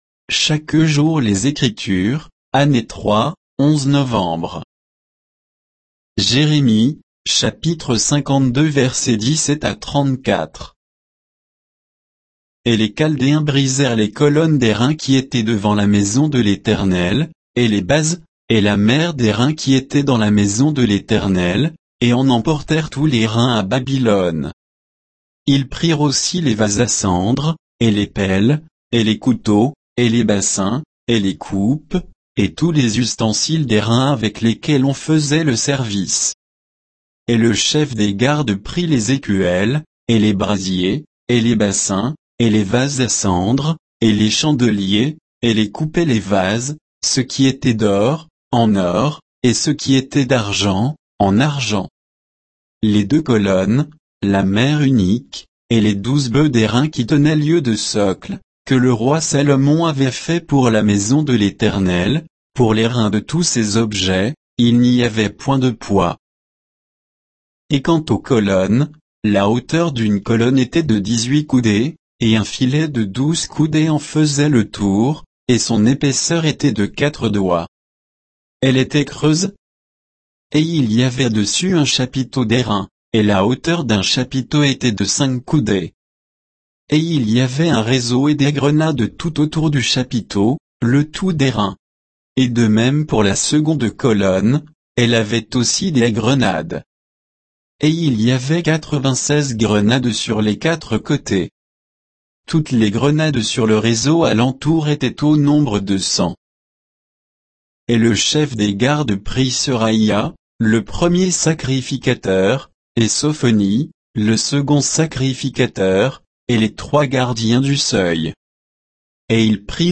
Méditation quoditienne de Chaque jour les Écritures sur Jérémie 52